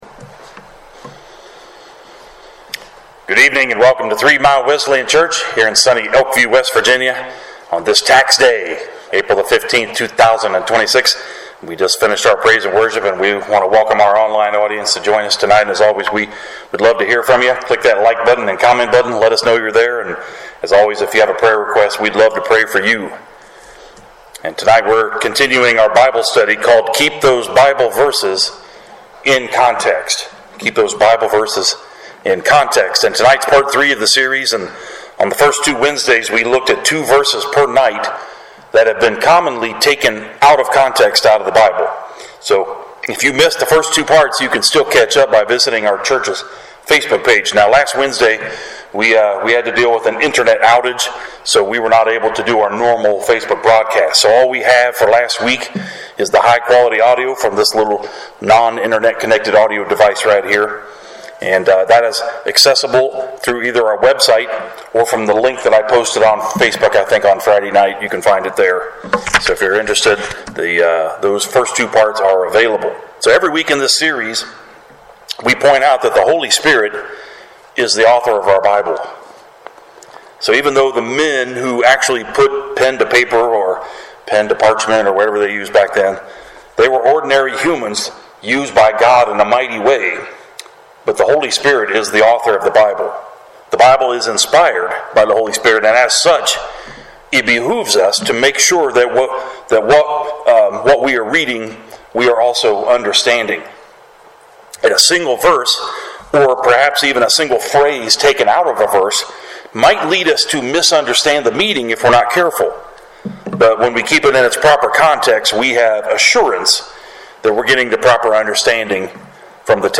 Sermons | Three Mile Wesleyan Church